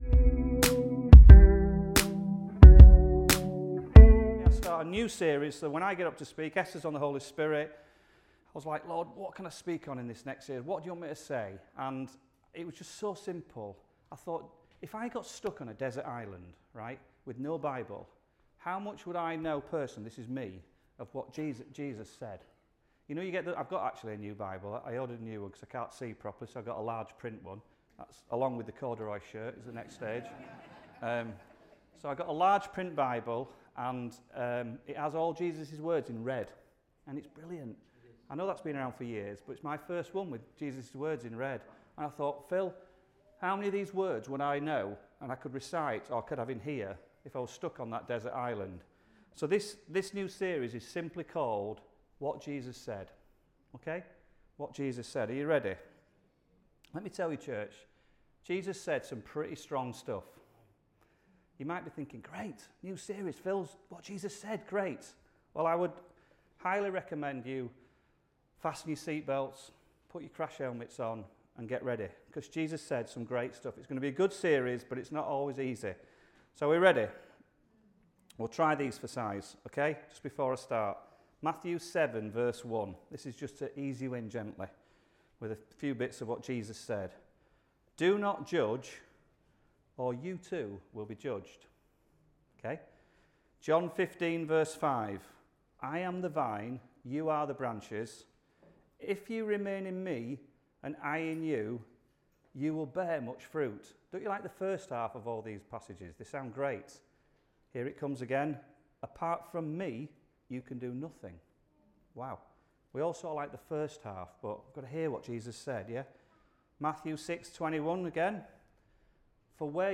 Sunday Messages What Jesus Said Part 1